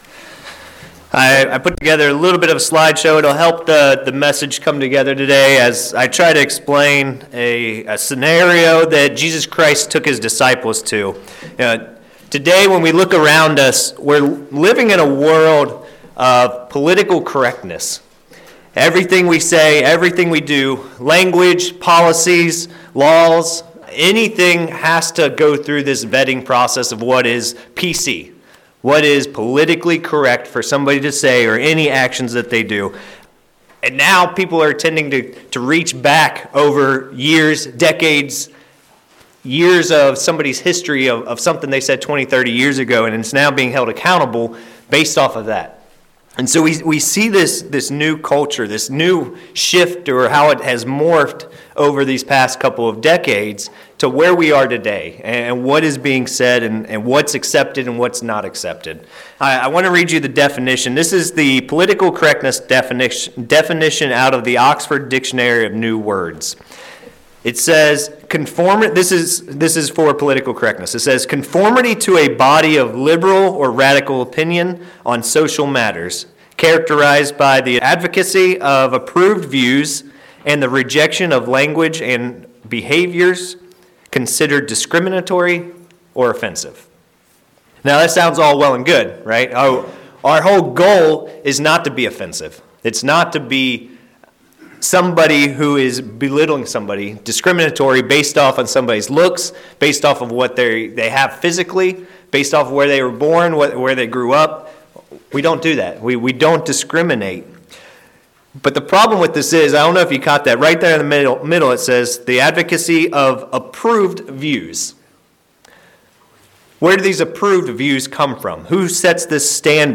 Sermons
Given in Ft. Wayne, IN